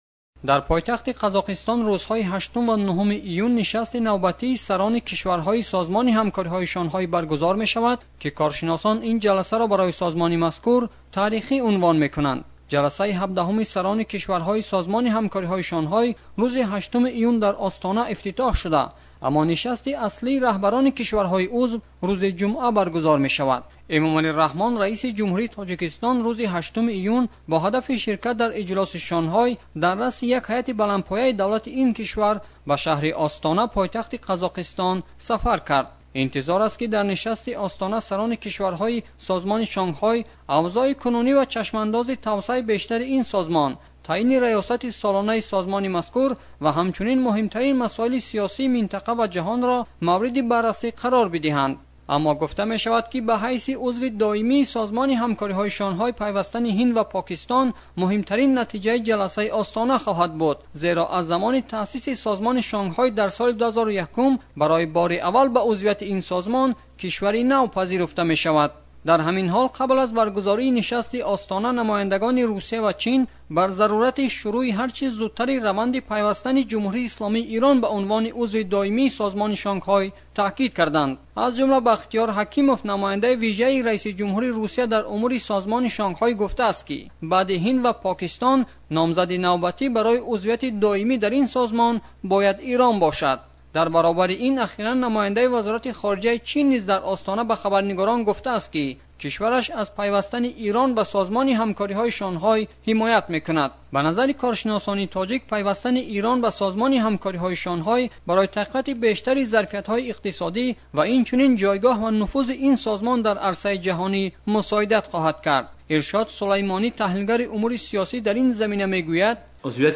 гузориш